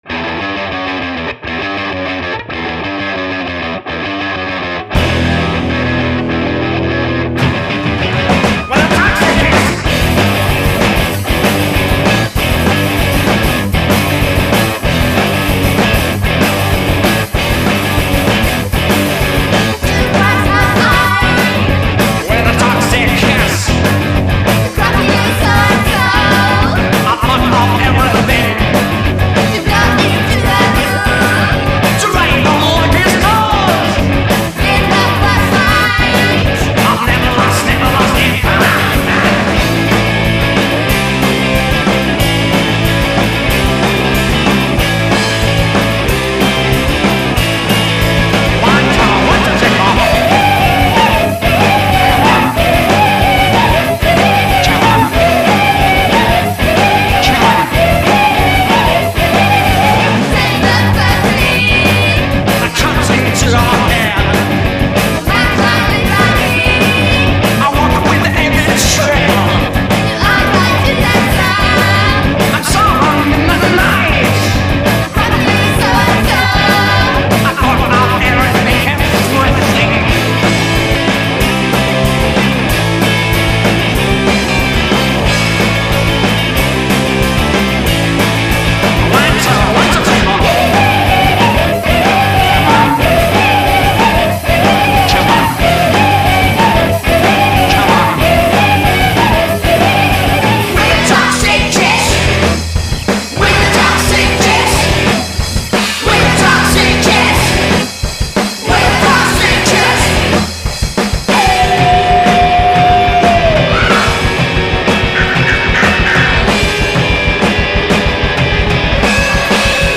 Rock Bubble Gun mutant, survolté et plein de féminité